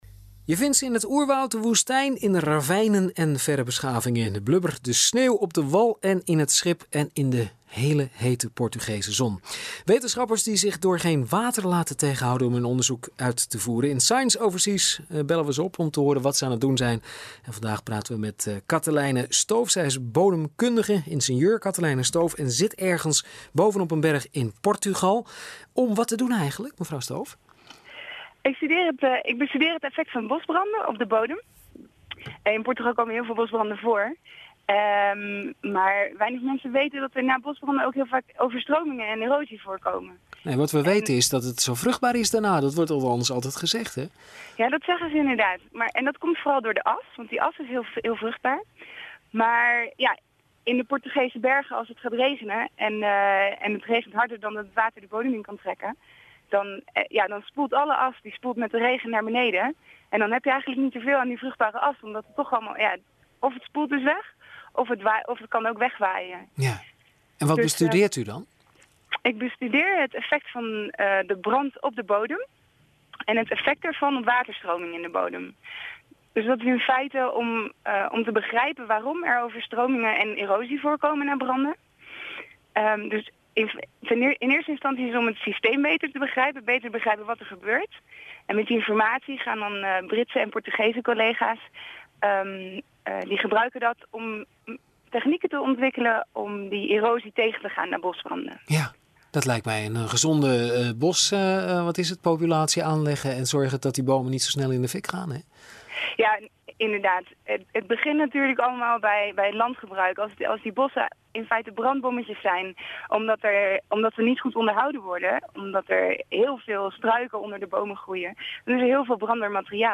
The following interviews were broadcast on Dutch, Belgian and Portuguese radio.